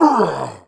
Sound / sound / monster / greenfrog_general / fall_1.wav
fall_1.wav